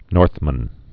(nôrthmən)